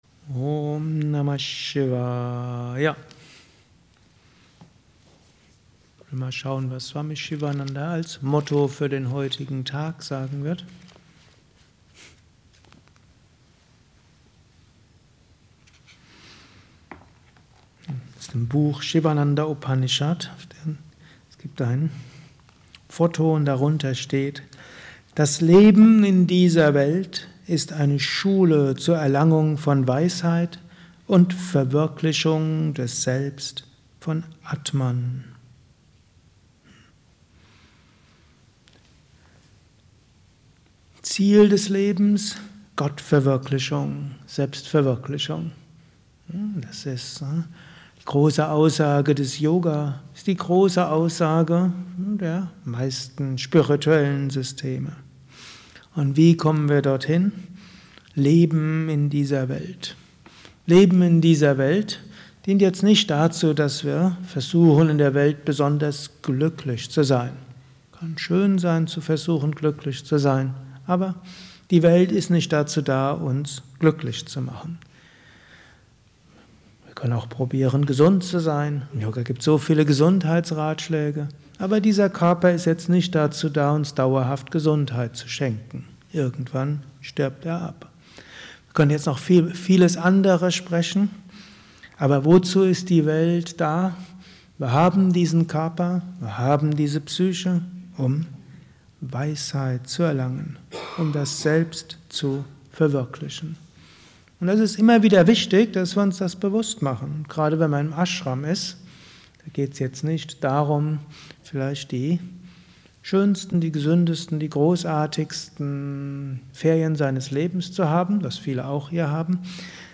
Gelesen im Anschluss nach einer Meditation im Haus Yoga Vidya Bad Meinberg.